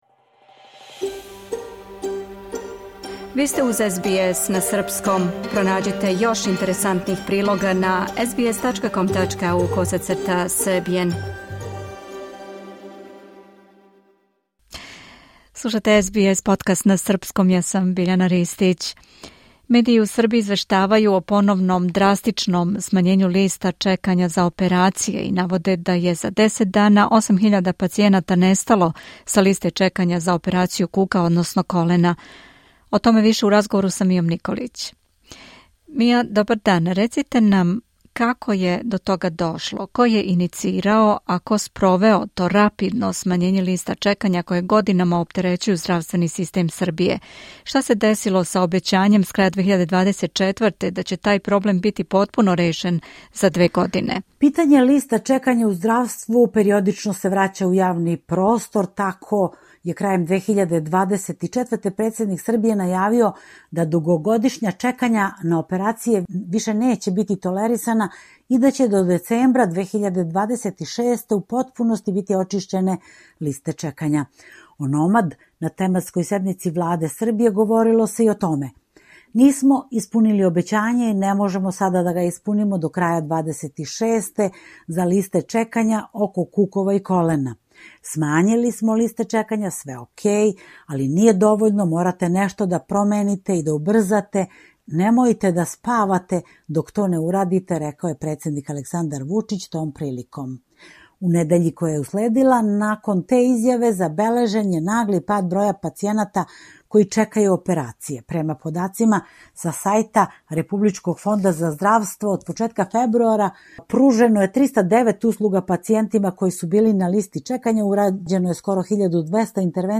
Медији у Србији извештавају о поновном драстичном смањењу листа чекања за операције и наводе да је за 10 дана 8.000 пацијената нестало са листе чекања за операцију кука, односно колена. О томе више у разговору